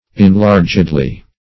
-- En*lar"ged*ly , adv.